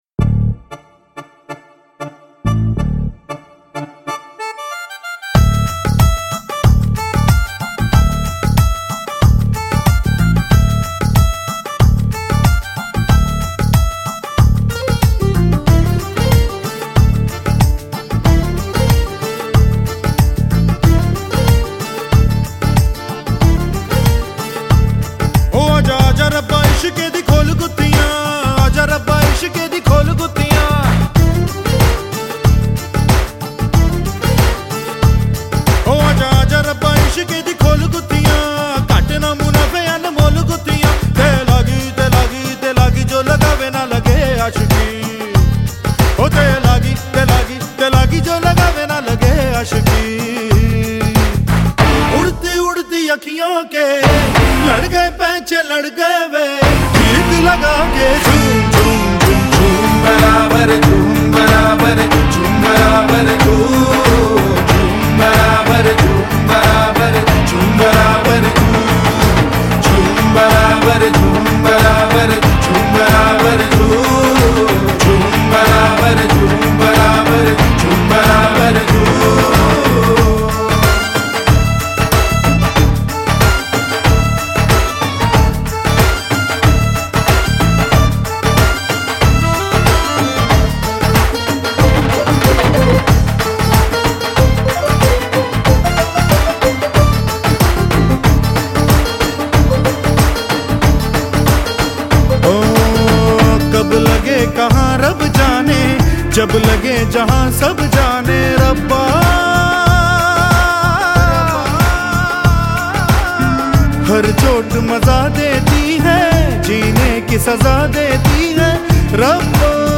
Bollywood track